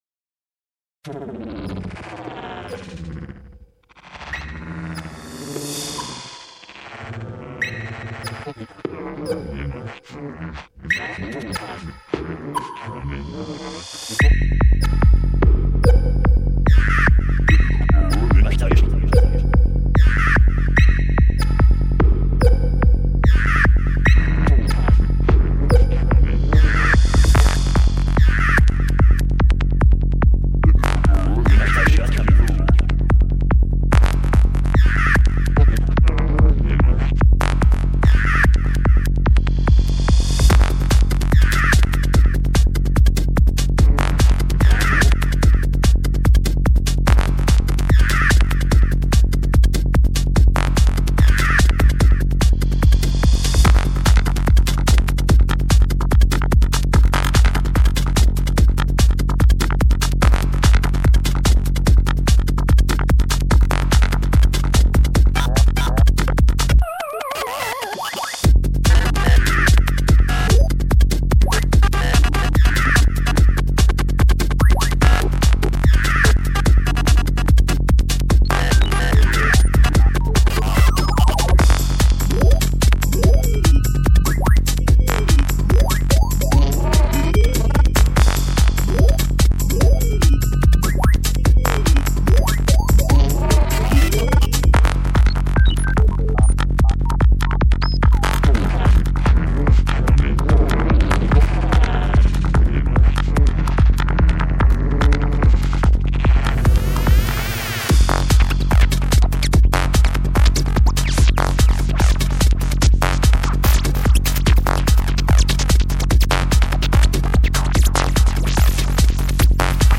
lofiHipHop